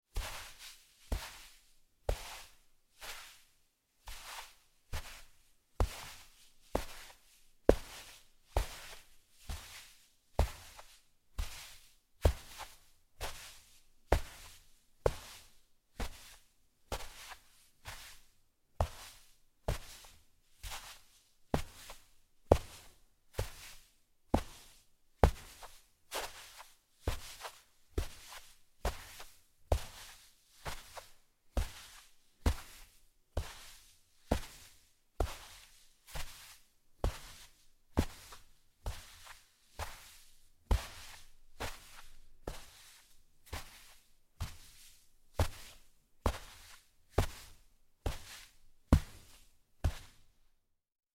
На этой странице собраны разнообразные звуки песка: от шуршания под ногами до шелеста дюн на ветру.
Прогулка по теплому песку на пляже